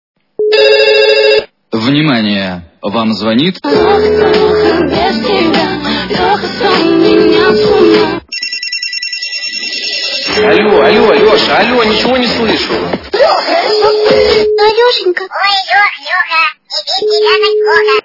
» Звуки » Люди фразы » Звонок от Лехи - Вам звонит Леха...
При прослушивании Звонок от Лехи - Вам звонит Леха... качество понижено и присутствуют гудки.